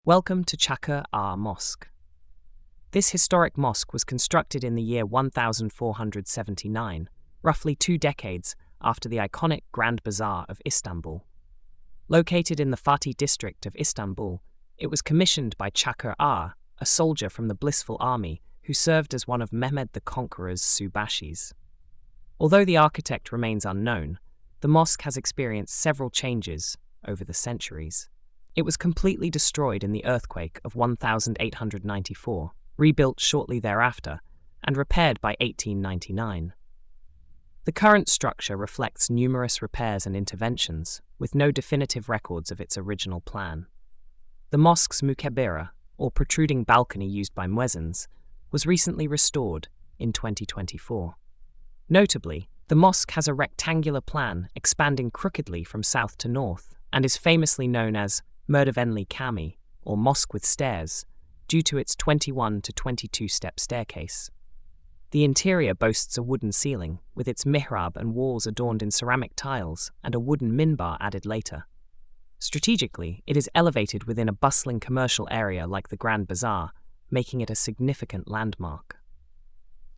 AUDIO NARRATION: